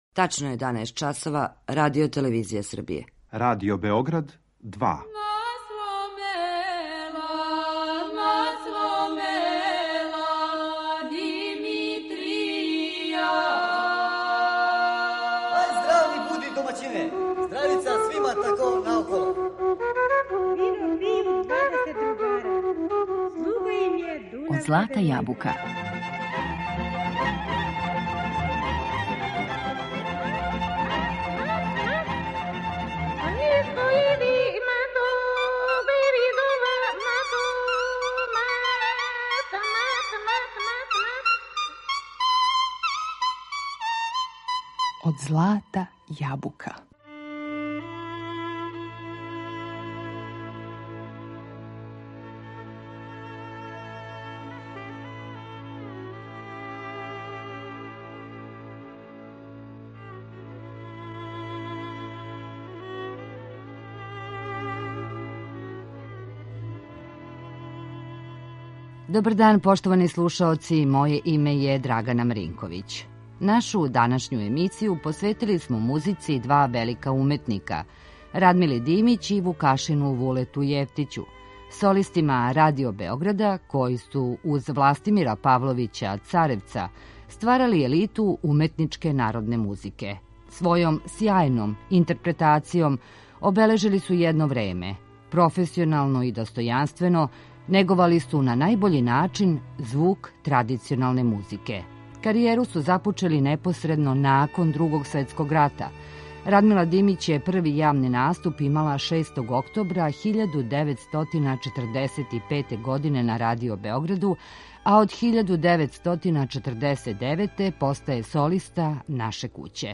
солиста Радио Београда
уметничке народне музике